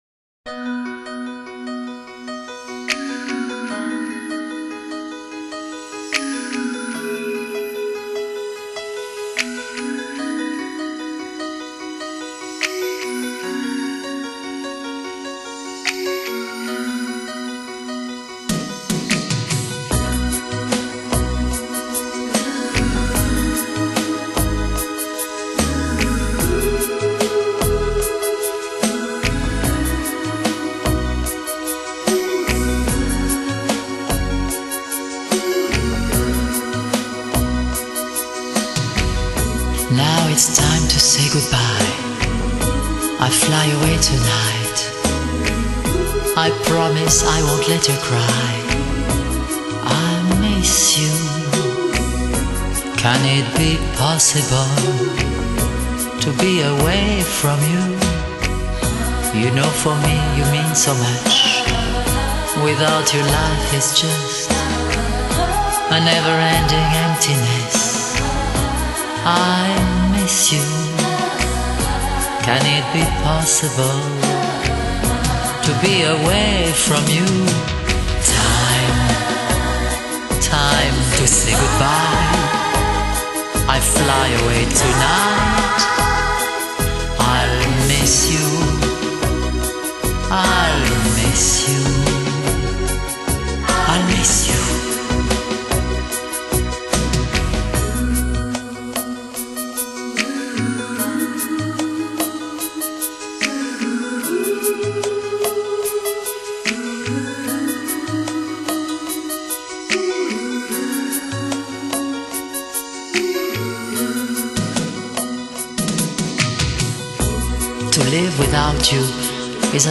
Genre: Pop, Disco | RAR 3% Rec.